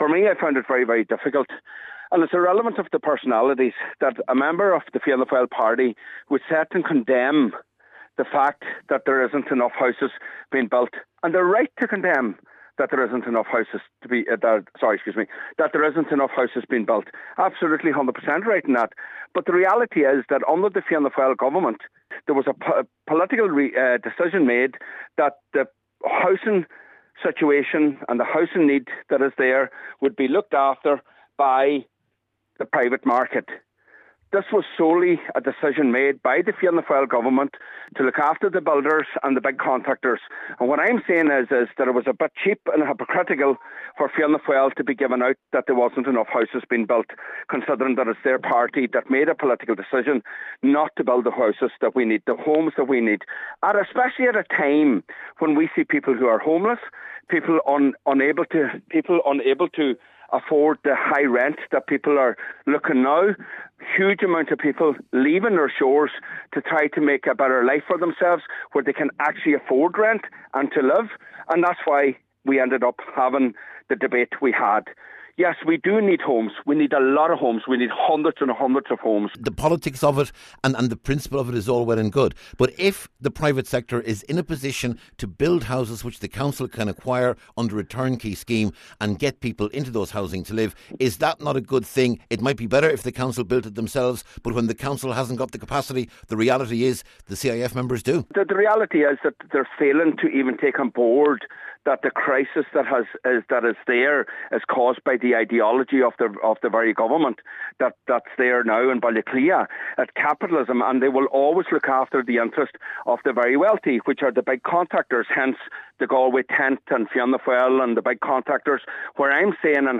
There were clashes at a special housing meeting at County House in Lifford this week between the Cathaoirleach of the Donegal Municipal District and a West Donegal independent councillor.